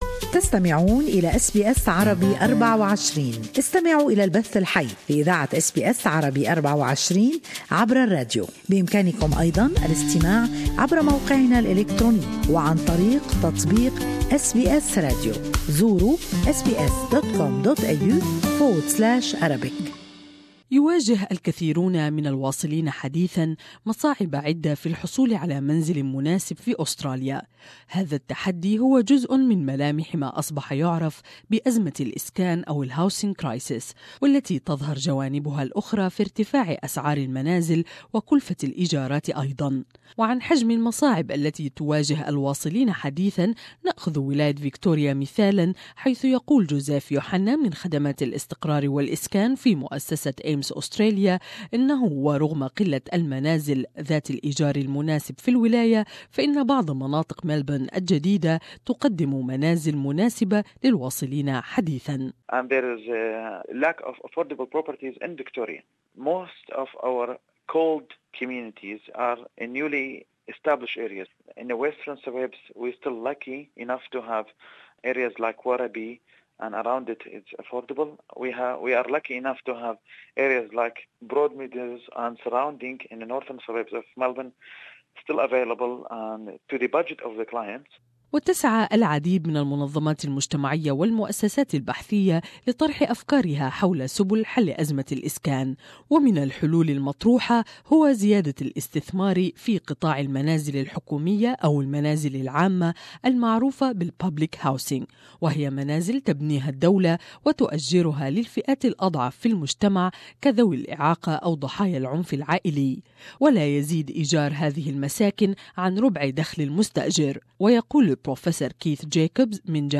They can apply through their state housing authority. More in this report